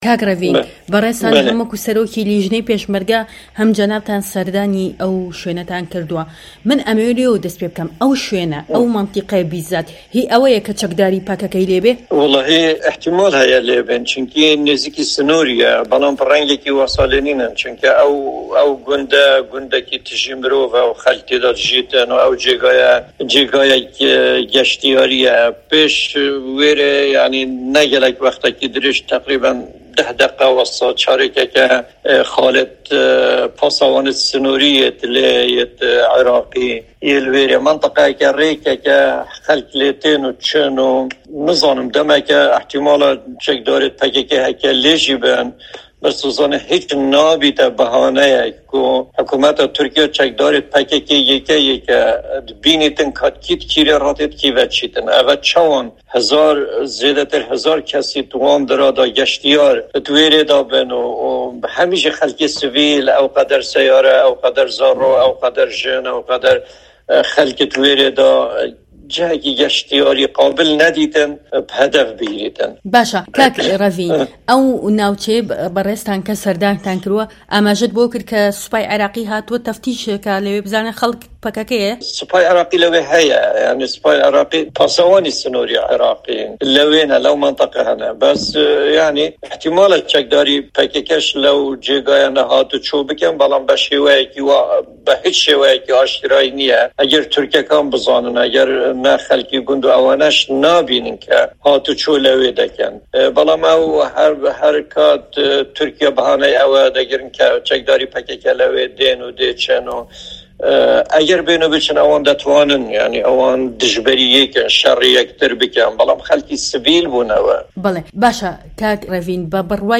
دەقی وتووێژەکەی ڕێڤینگ هروری لەم فایلە دەنگیەدا